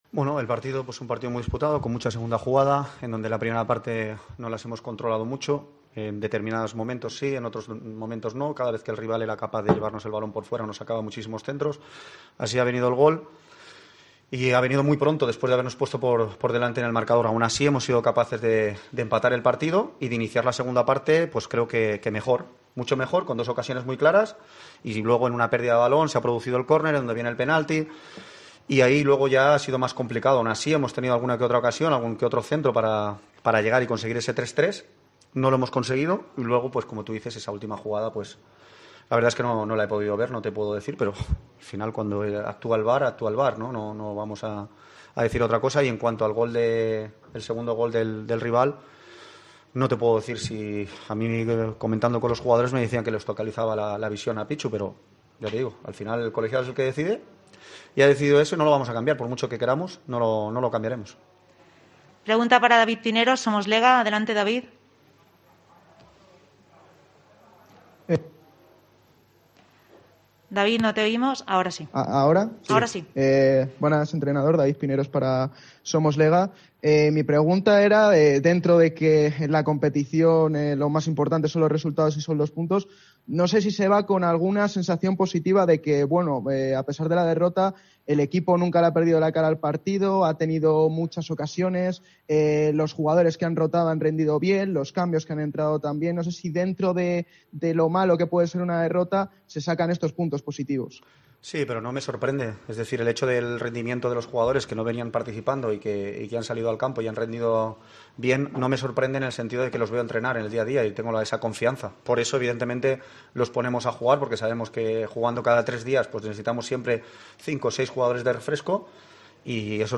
AUDIO: Escucha aquí las declaraciones del míster del Leganés, José Luis Martí, y del entrenador de la Deportiva, Jon Pérez Bolo